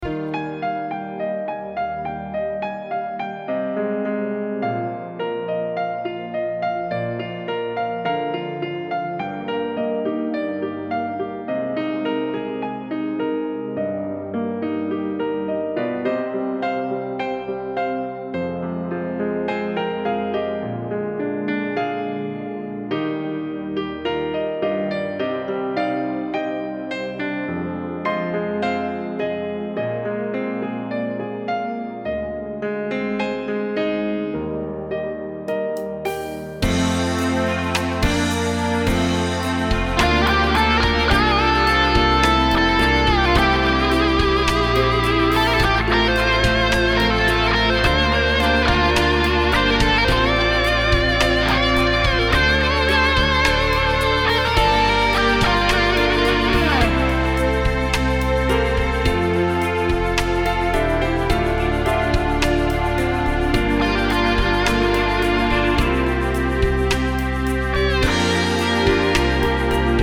Tonalità originale